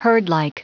Prononciation du mot herdlike en anglais (fichier audio)
Prononciation du mot : herdlike